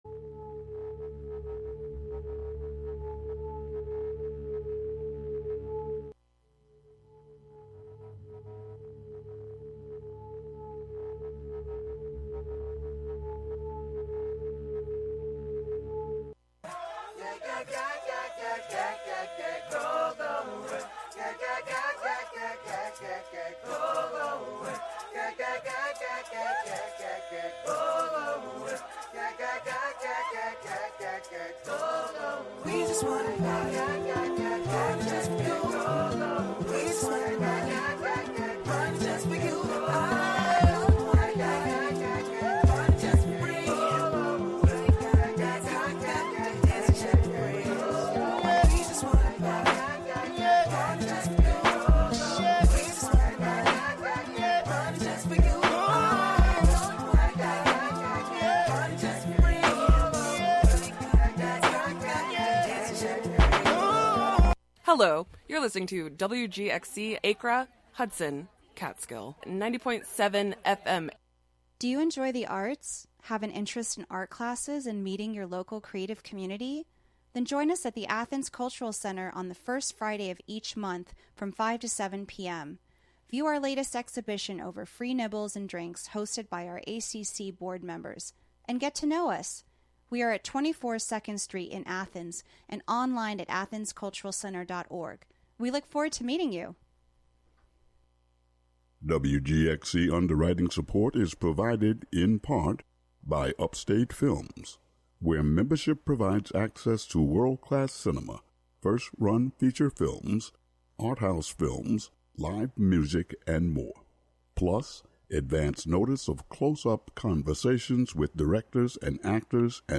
Contributions from many WGXC programmers.